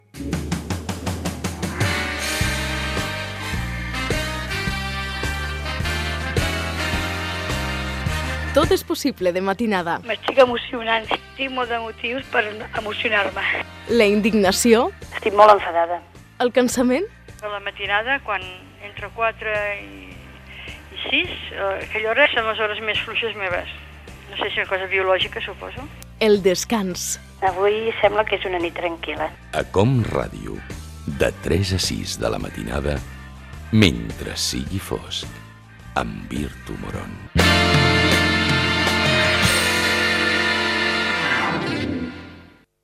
7342a2396a8164a819f0be5c27127feeaa8c50d0.mp3 Títol COM Ràdio Emissora COM Ràdio Barcelona Cadena COM Ràdio Titularitat Pública nacional Nom programa Mentre sigui fosc Descripció Promoció del programa.